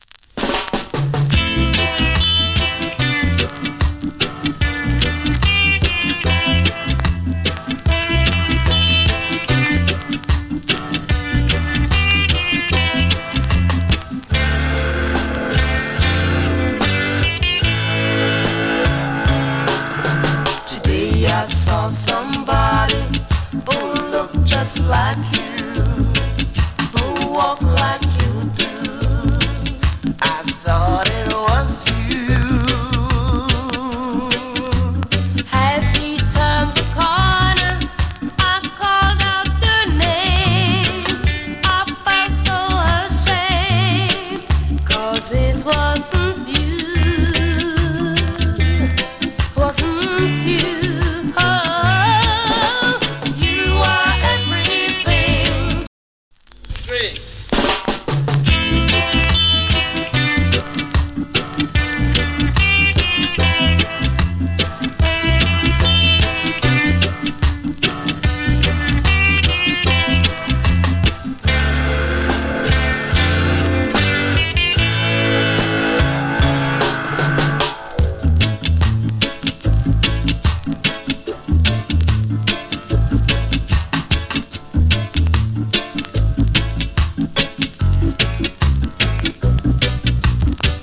FINE COVER !! DUET VOCAL. SOULFULL REGGAE !